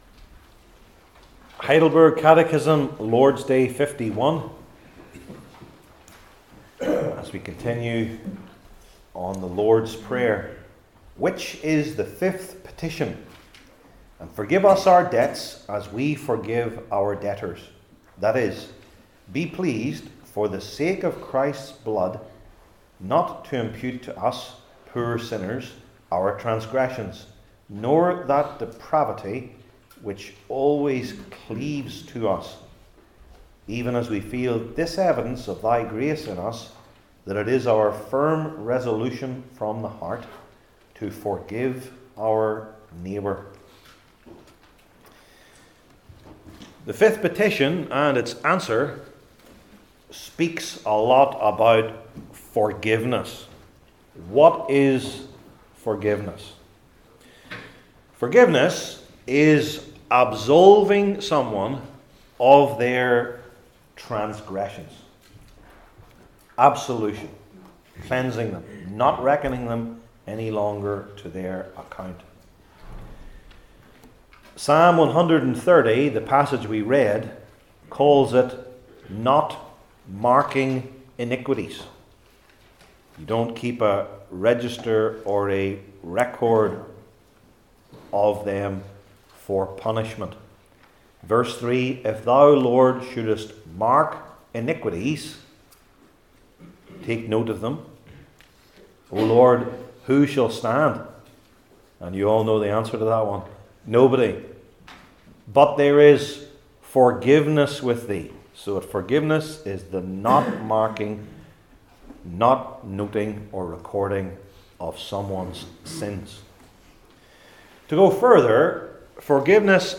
Heidelberg Catechism Sermons I. In Eternity II.